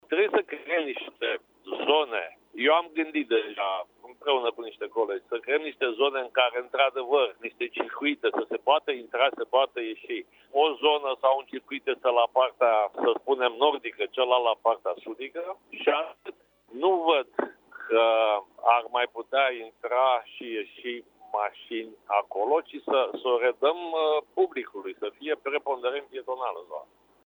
Primarul Vergil Chițac spune că trebuie să existe circuite prin care să se poată intra și ieși: